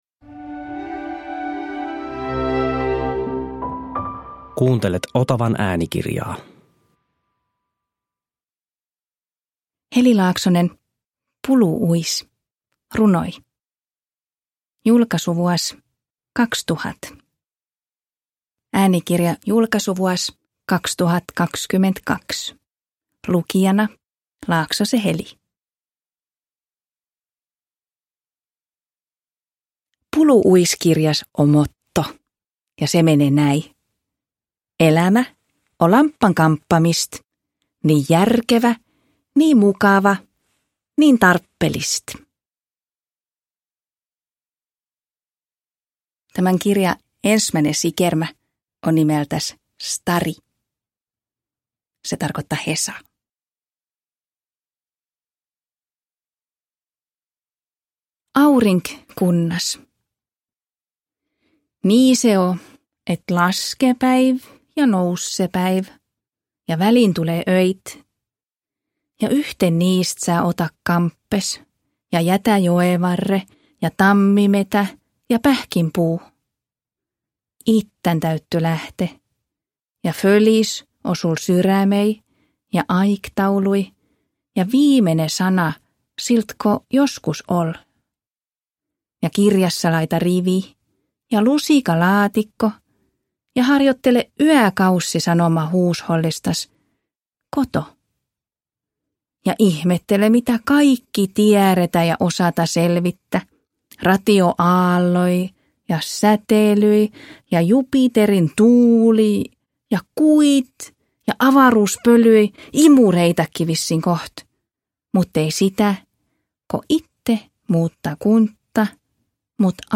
Pulu uis – Ljudbok – Laddas ner
Äänikirjan lukee Heli Laaksonen.
Uppläsare: Heli Laaksonen